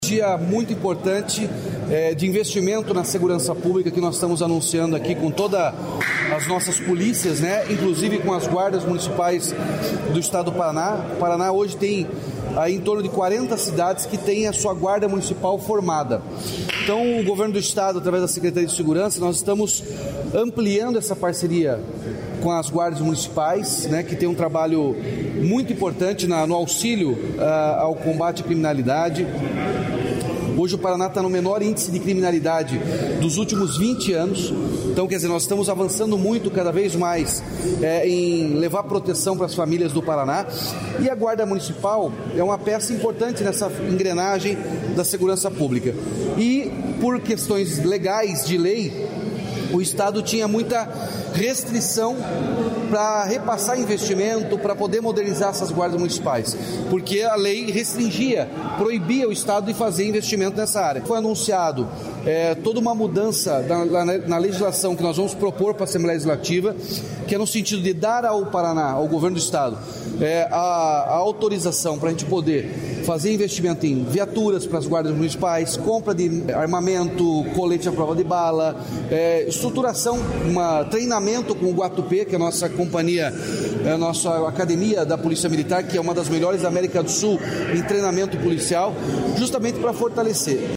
Sonora do governador Ratinho Junior sobre a integração entre forças de segurança